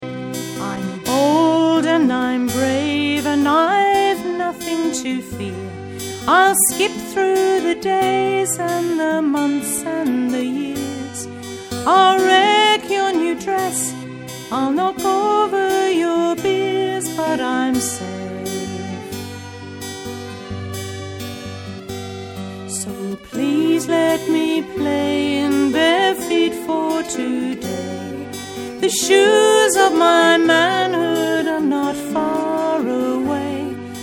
folk singer